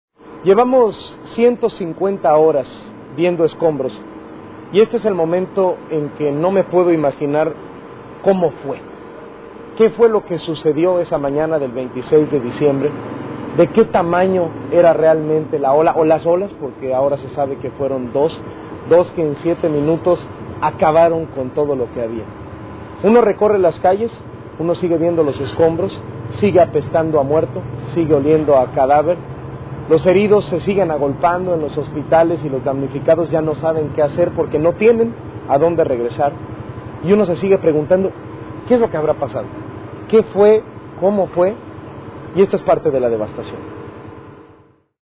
Carlos Loret de Mola de Noticieros Televisa reporta, atraves de un enlace con el periodista López Doriga, acerca de la tragedia ocurrida en Tailandia el 22 de diciembre de 2004, según en Wikipedia: